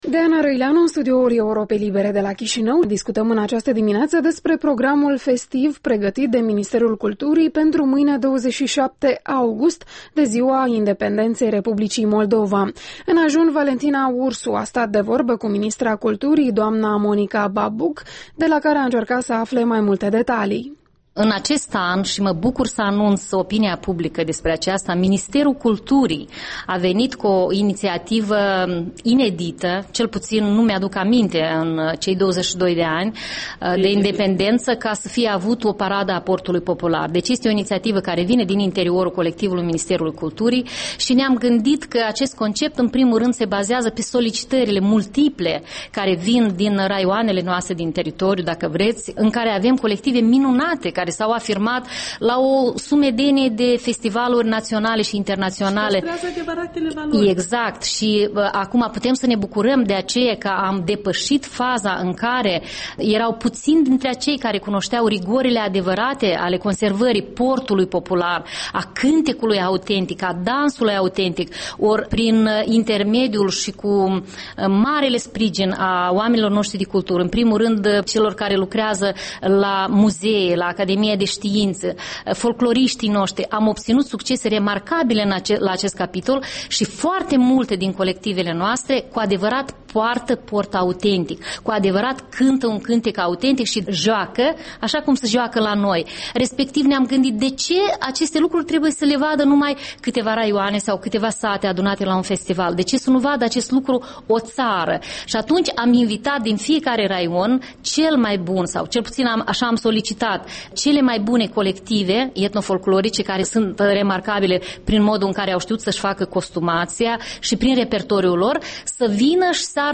Interviul dimineții: cu Monica Babuc despre pregătirile pentru Ziua Independenței